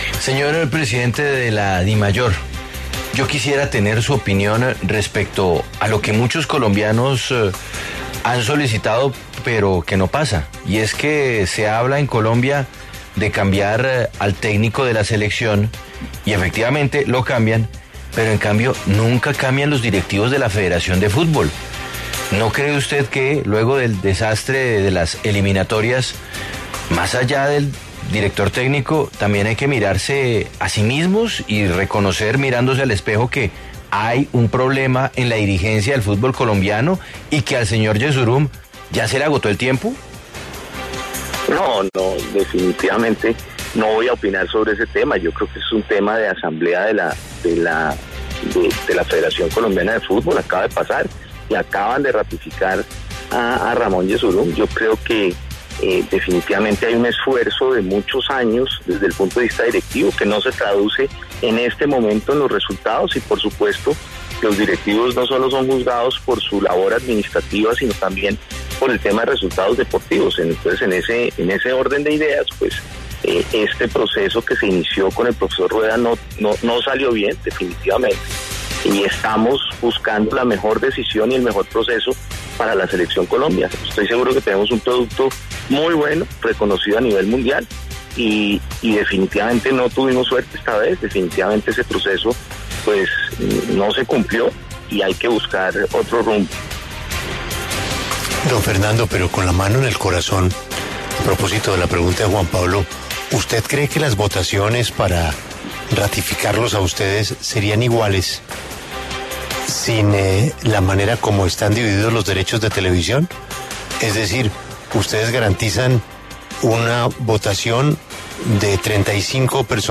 En entrevista con La W